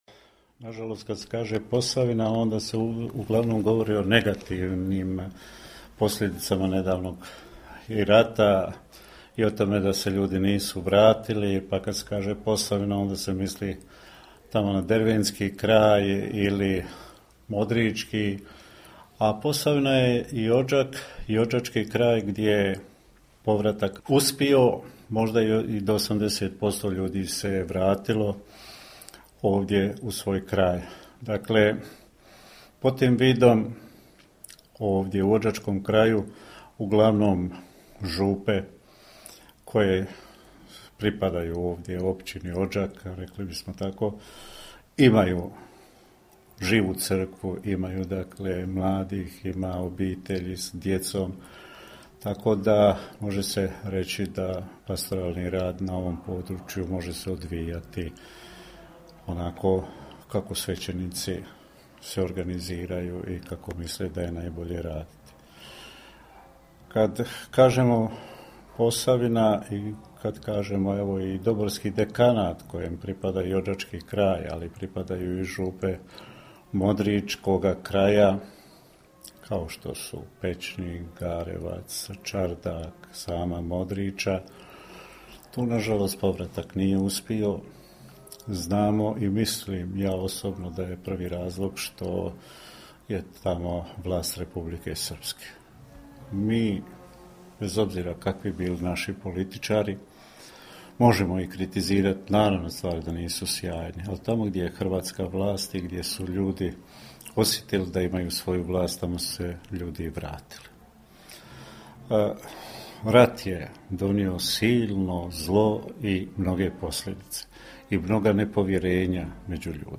Posljednji intervju